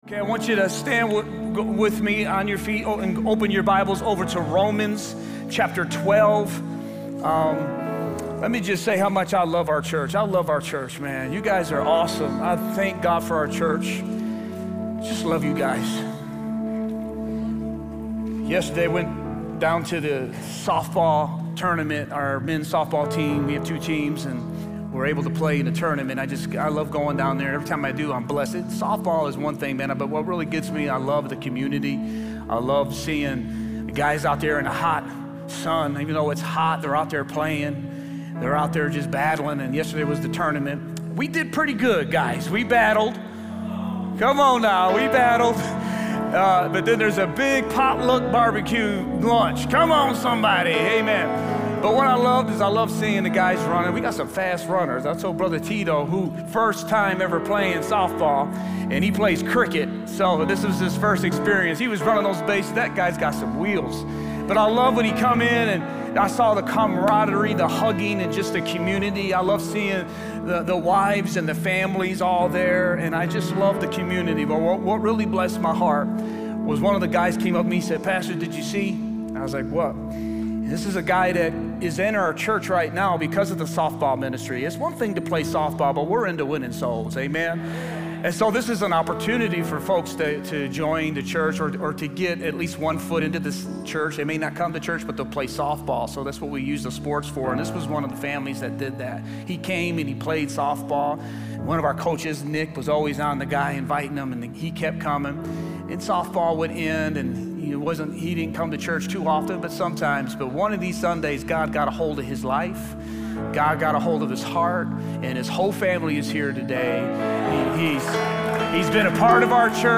Sermons | River of Life Church